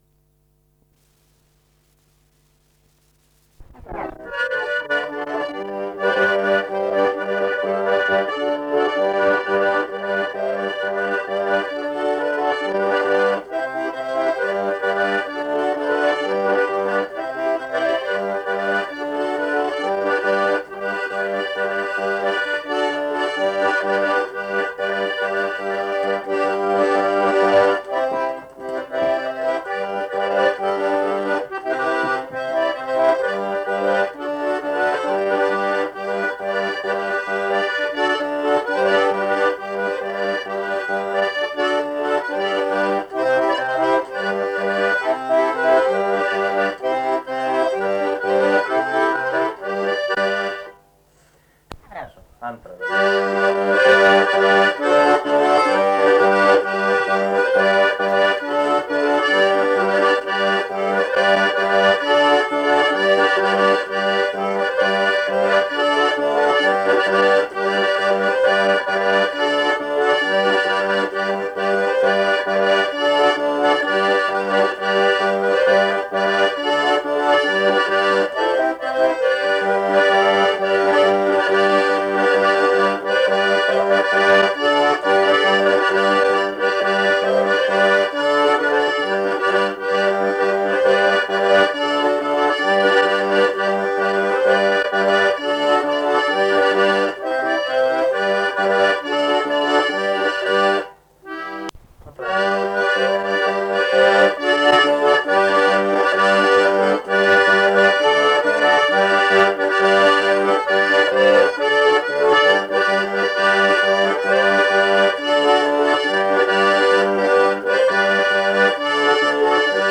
daina
Polka
Kadrilis